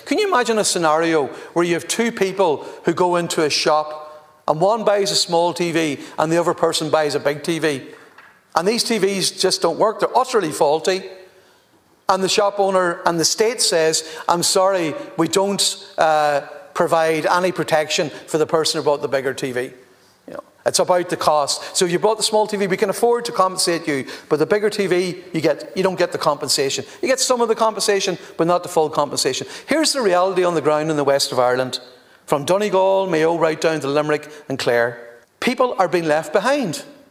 The second stage of the bill introducing amendments to Defective Concrete Block redress legislation were carried out in the Dáil chamber yesterday evening, with Donegal TDs having their voices heard.